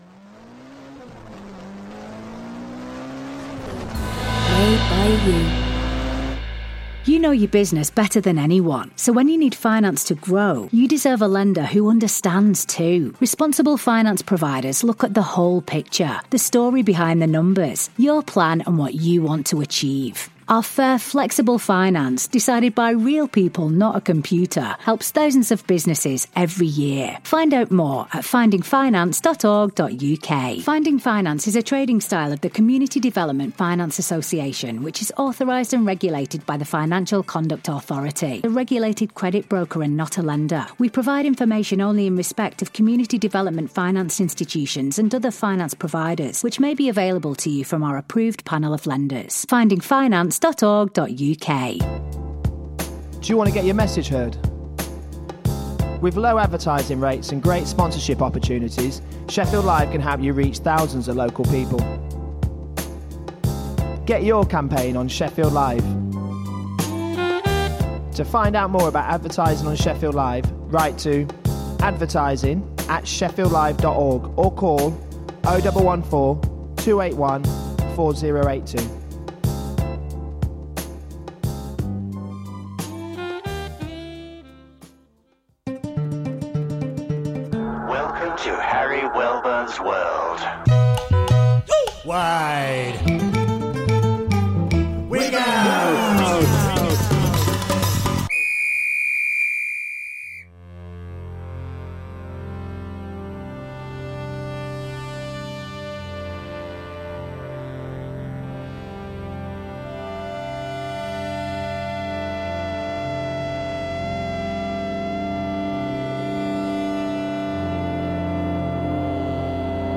World Music to move & groove to. New, current, classic & obscure tracks.